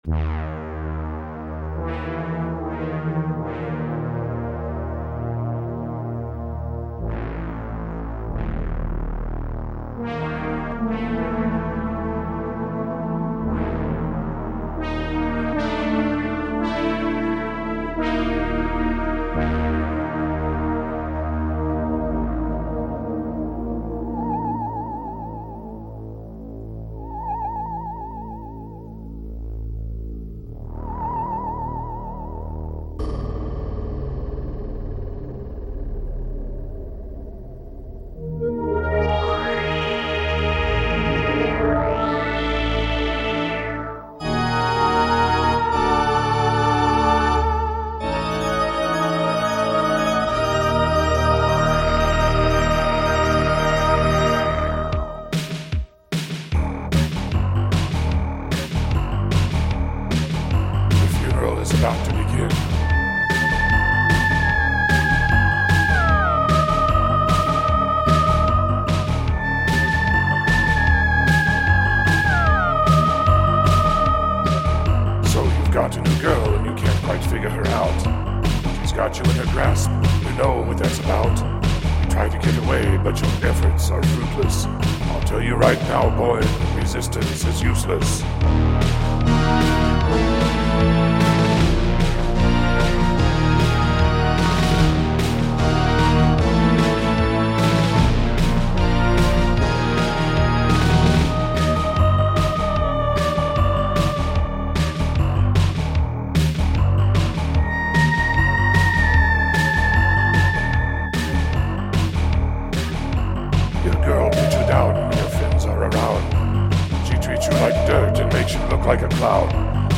Resistance Is Useless Resistance Is Useless was sequenced on an Ensoniq VFX-SD Version 2 with a SQ-80 slaved by MIDI. Both synths were sent through a mixer directly to the recorder (no external effects) with vocals overdubbed later. The SQ-80 does the Theremin and all analog synth sounds. The VFX does pipe organ, clavinet, "bass", percussion, and ghosts.